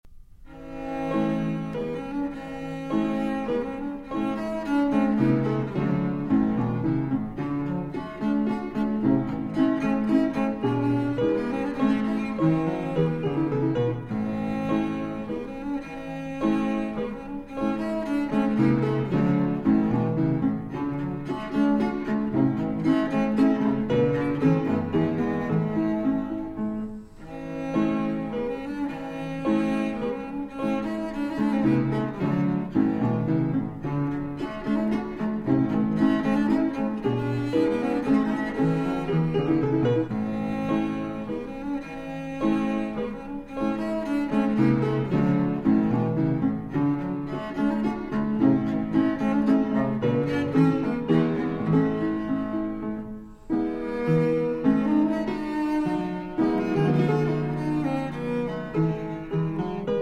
World premiere recording of the accompanied cello sonatas
Harpsichord
Classical Cello